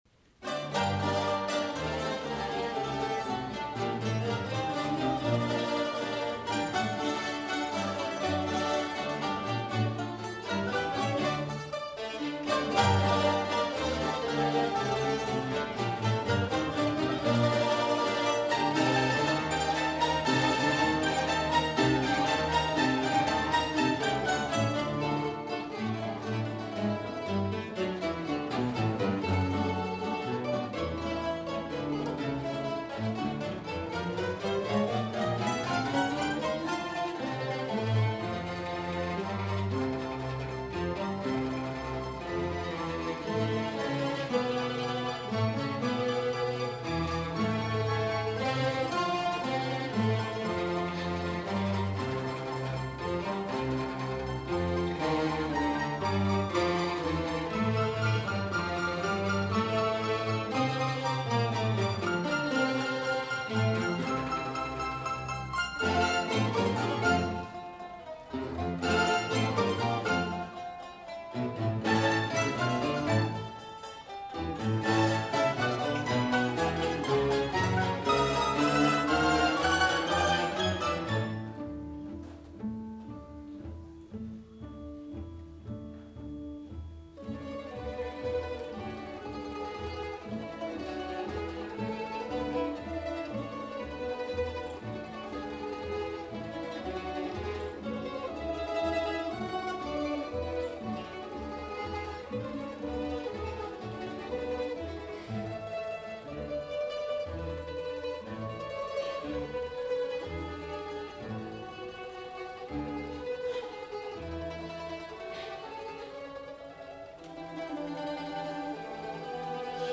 第１１回結成２０周年記念定期演奏会
ところパレット市民劇場
１部　クラシックアレンジ＆マンドリンオリジナル